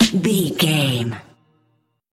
Aeolian/Minor
drum machine
synthesiser
electric piano
hip hop
Funk
neo soul
acid jazz
confident
energetic
bouncy
funky